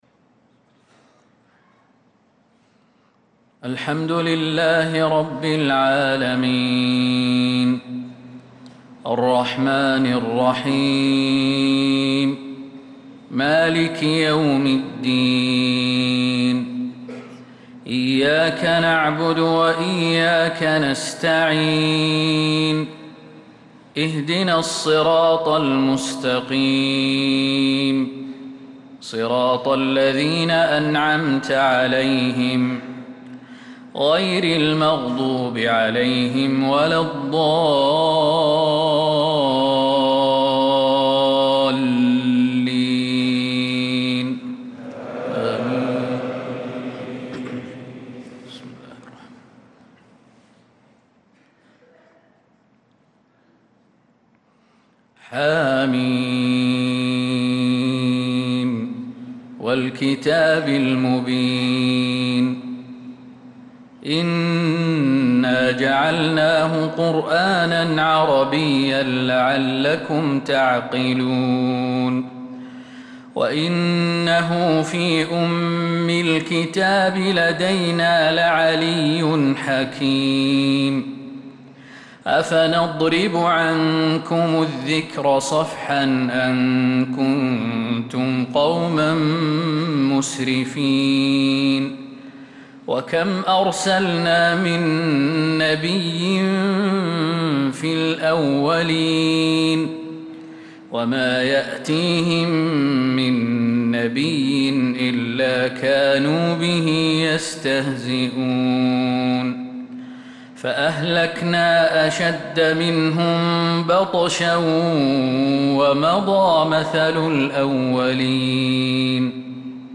صلاة الفجر للقارئ خالد المهنا 13 شوال 1445 هـ
تِلَاوَات الْحَرَمَيْن .